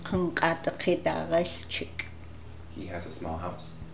Small /…chek’/